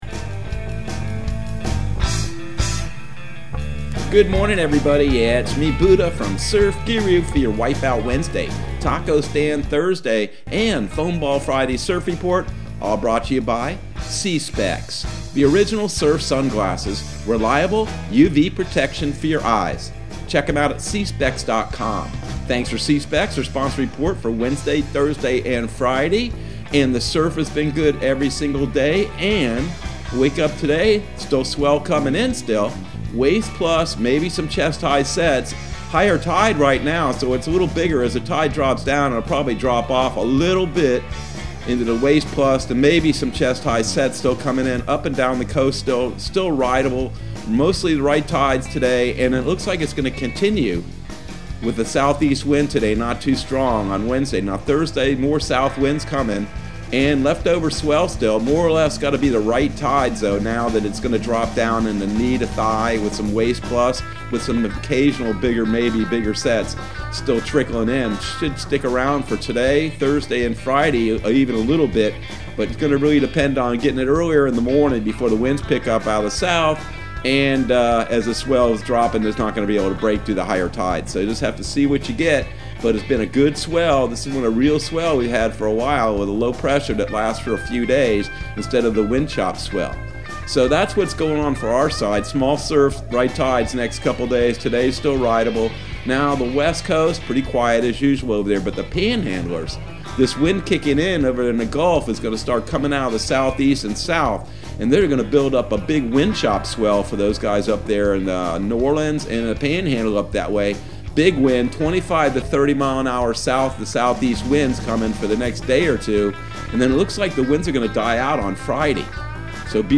Surf Guru Surf Report and Forecast 03/24/2021 Audio surf report and surf forecast on March 24 for Central Florida and the Southeast.